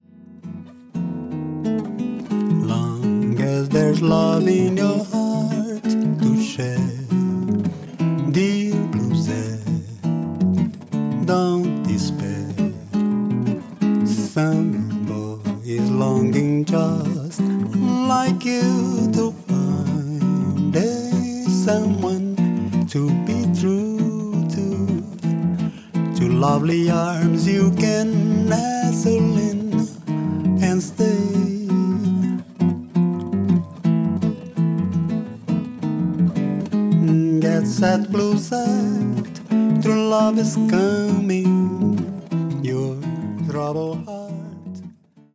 ギターと声だけ。
まろやかなその歌は円熟の極み。ギター弾き語りでジャズやブラジル音楽のスタンダードを歌う。
vo,g